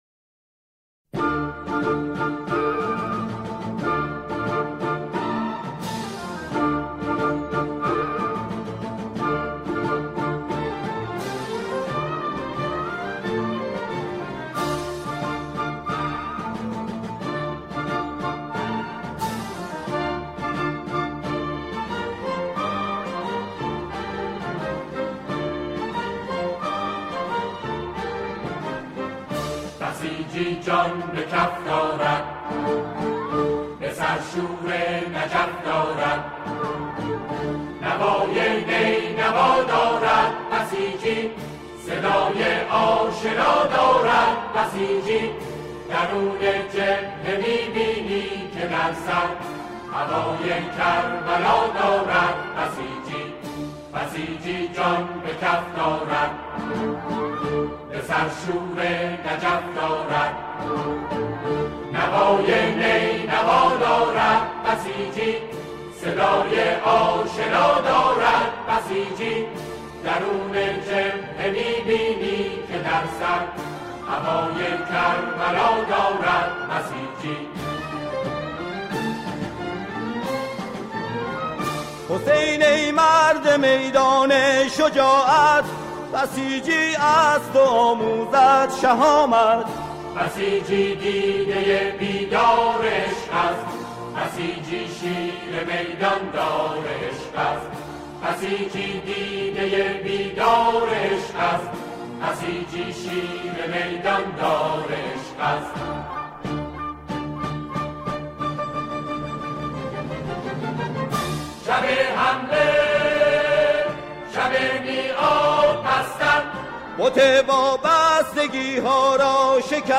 آنها در این قطعه، شعری را درباره بسیج همخوانی می‌کنند.